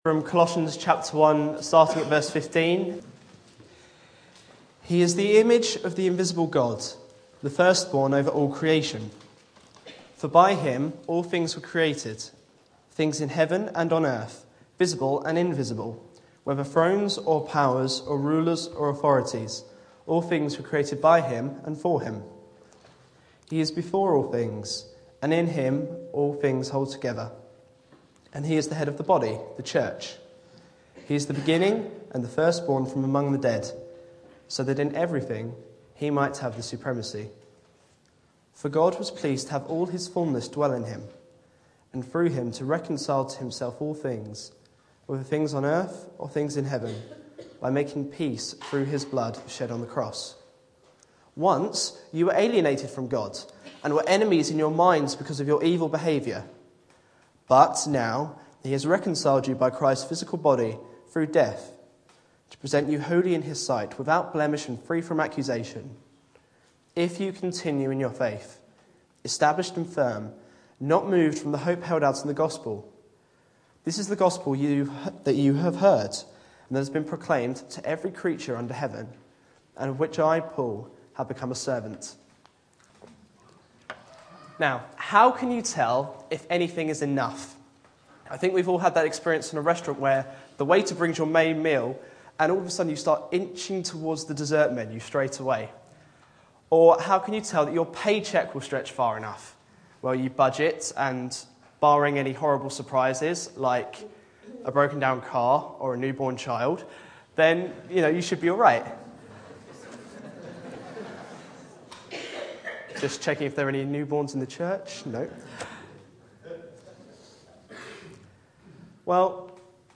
Back to Sermons Reconciling all things